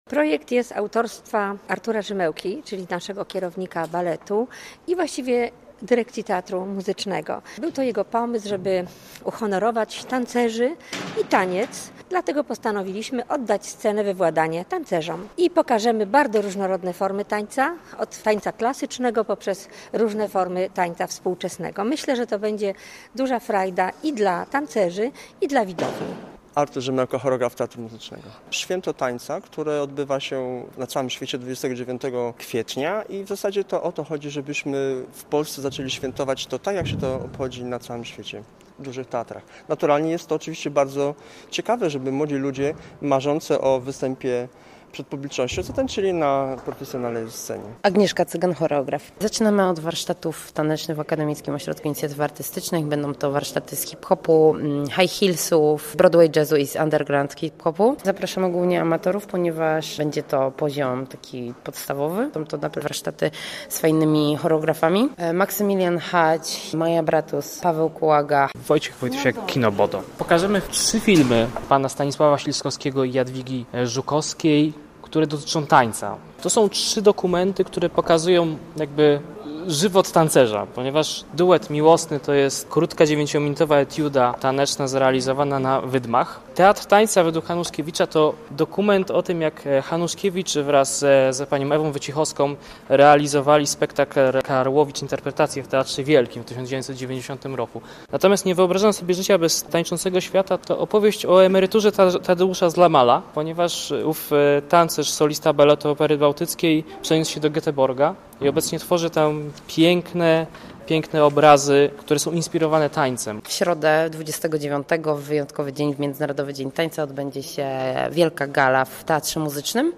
Posłuchaj relacji naszej reporterki i dowiedz się więcej: